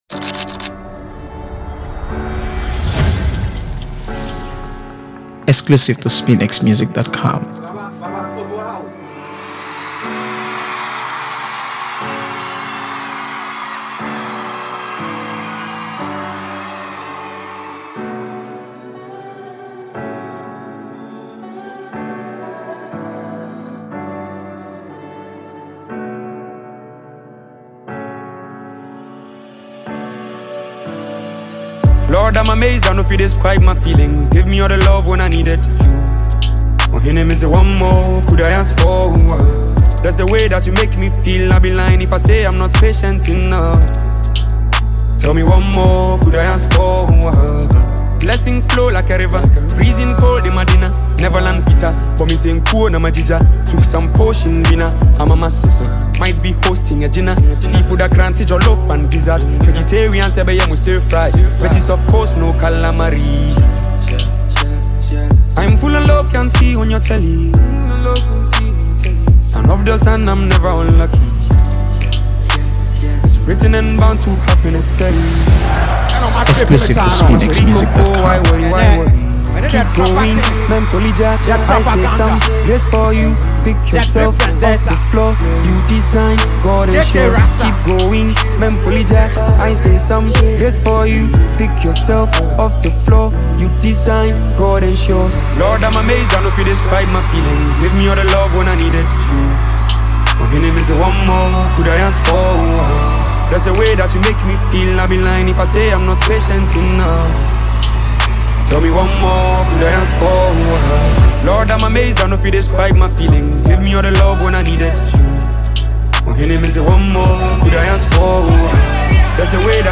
AfroBeats | AfroBeats songs
Ghanaian singer-songwriter
With its captivating lyrics and infectious melody